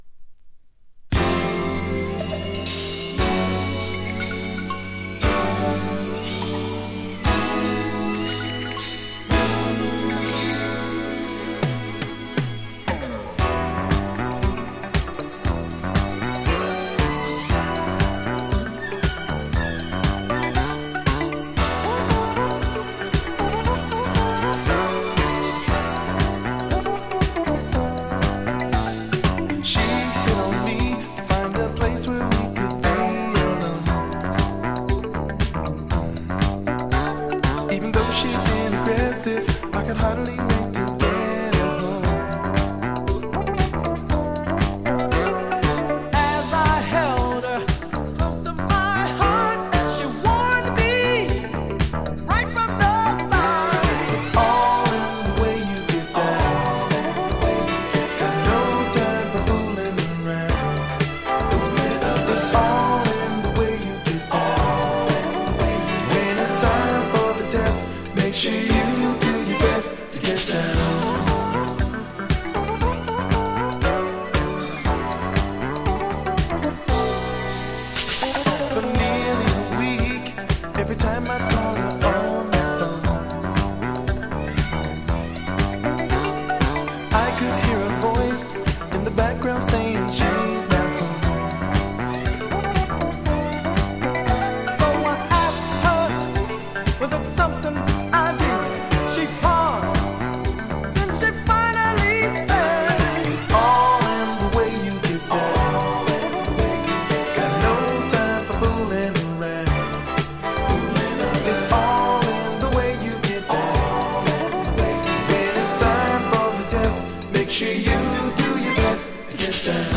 Disco Funk at its bestな超ご機嫌な仕上がりの名盤！
過去に販売した盤（日本盤のEX）からの録音です。